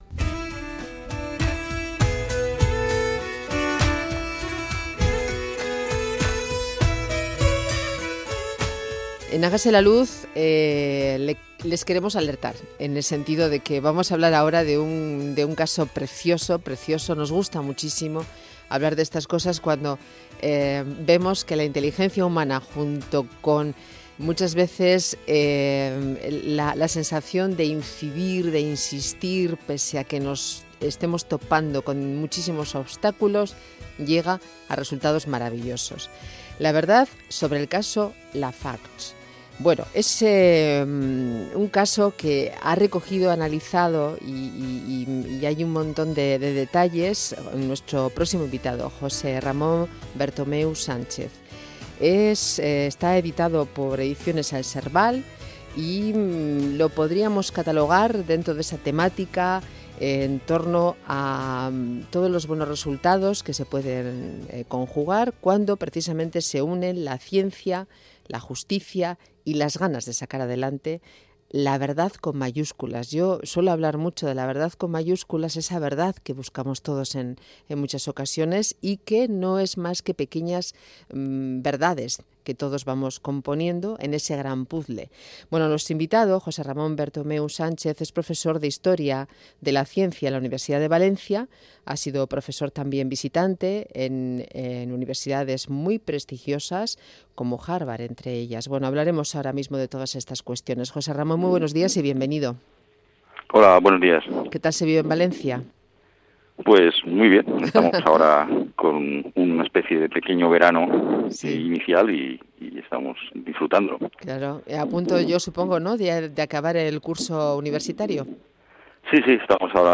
El proceso se conoció como ‘la batalla del arsénico’. Entrevistamos